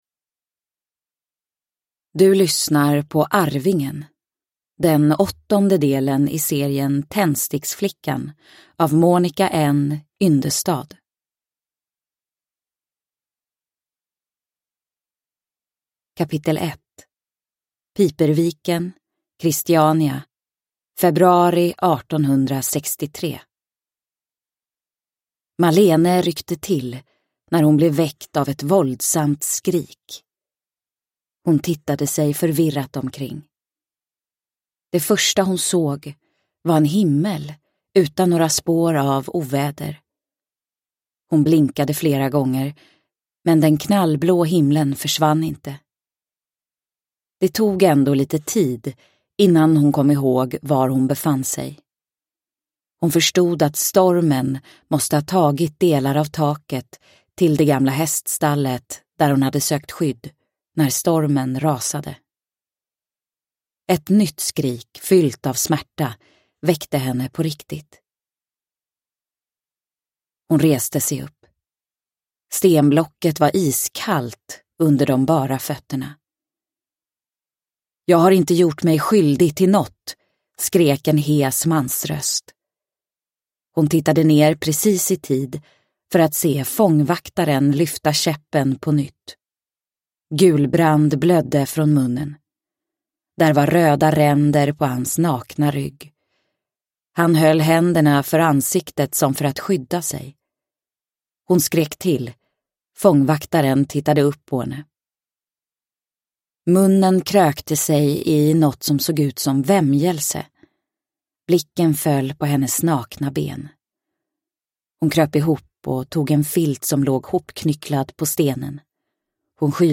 Arvingen – Ljudbok – Laddas ner